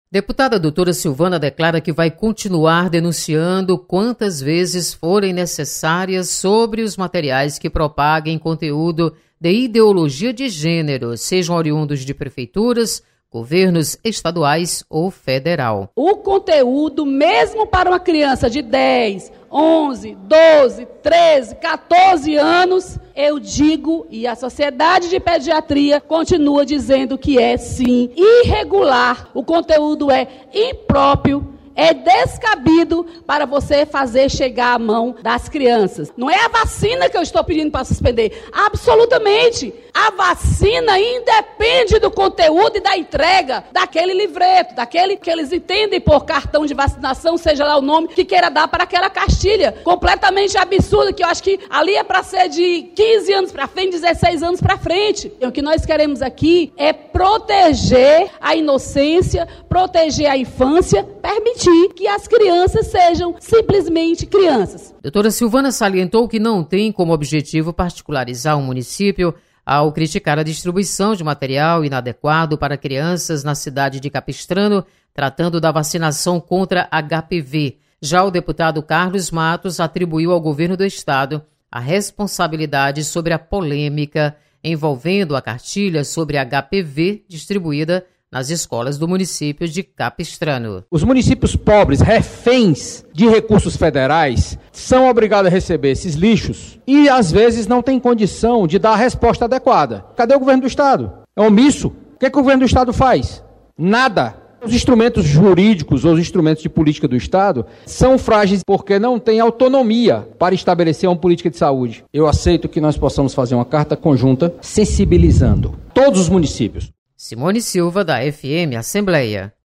Plenário
Deputado Carlos Matos debate com deputada Doutora Silvana sobre responsabilidade de distribuição de material sobre ideologia de gênero.